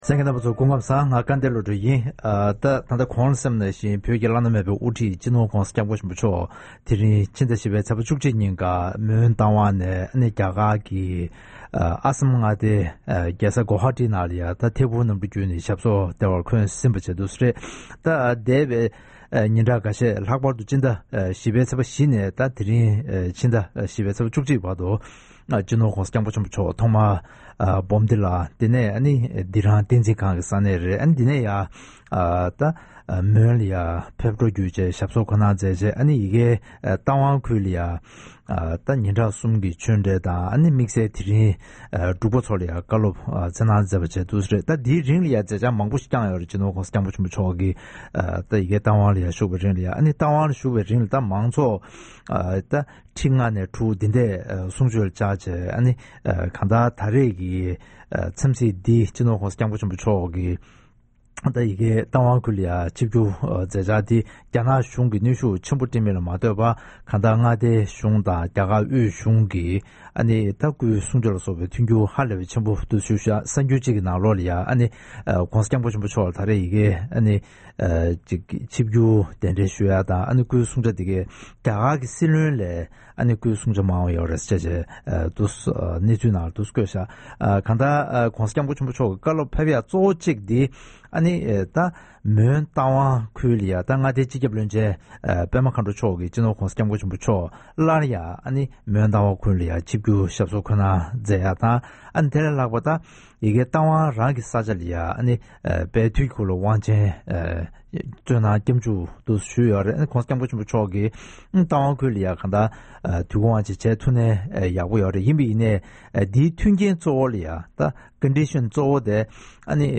༸གོང་ས་མཆོག་གི་ད་རེས་ཀྱི་ཆིབས་བསྒྱུར་དང་འབྲེལ་བའི་སྐོར་ལ་འབྲེལ་ཡོད་མི་སྣ་ཁག་ཅིག་དང་ལྷན་དུ་གླེང་མོལ་ཞུས་པར་གསན་རོགས་གནང་།